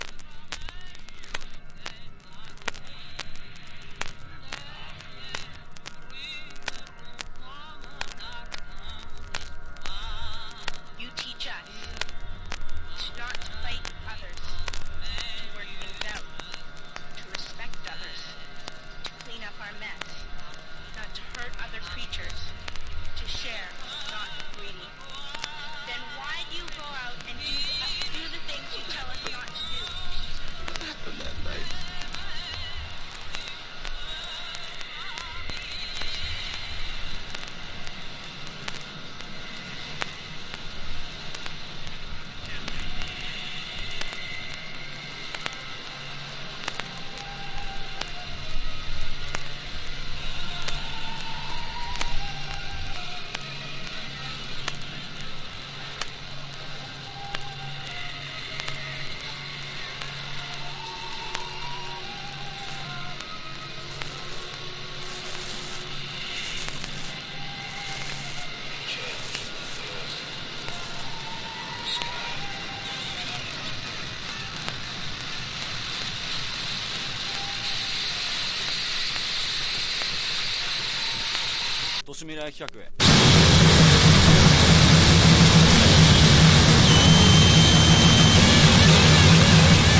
ダークに突き進むノイズ・コアで轟音が響き渡る
PUNK / HARDCORE